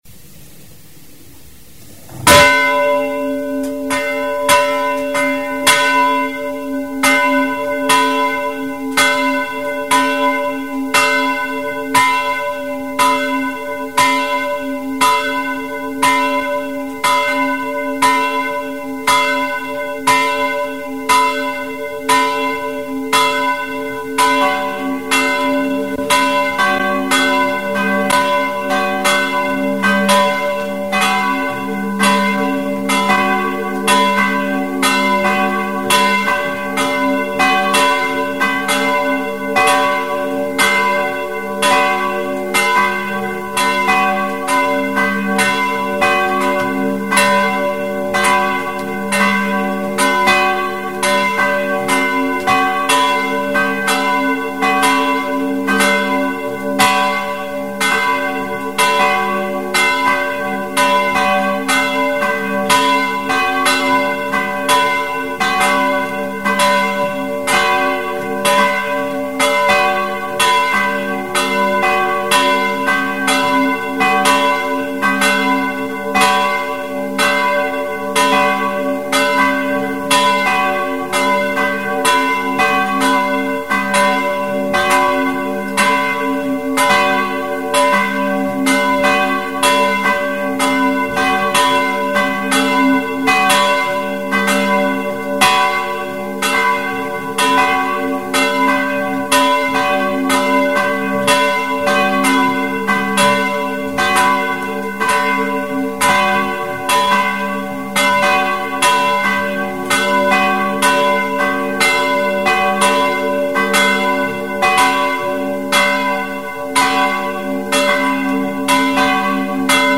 Lutheran church bells ringing in Semlak (turn on your speakers)
Glocken - gr. + kl.- Evang. Kir. Semlak - 2007.mp3